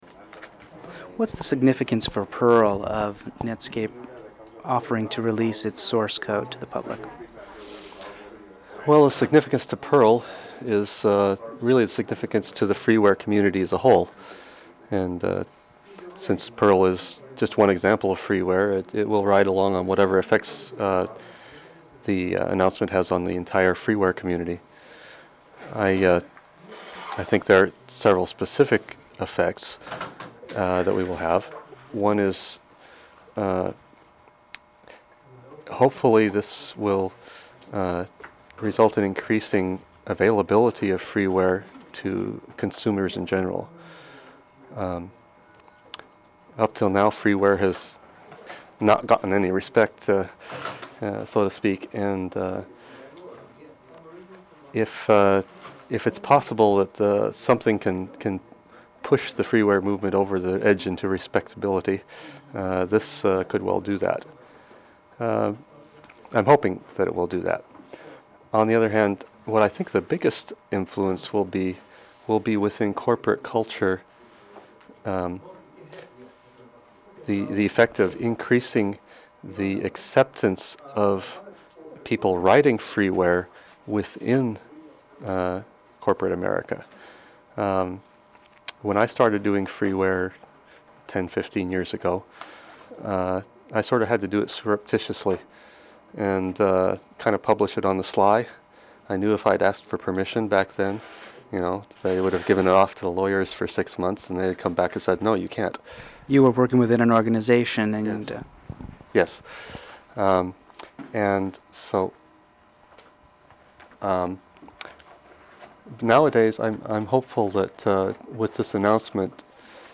Interviewed